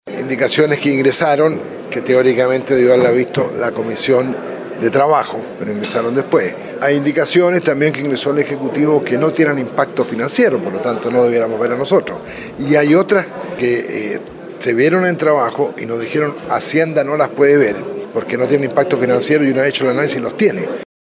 Así lo explicó el diputado DC, Pablo Lorenzini, detallando otros problemas de orden administrativo.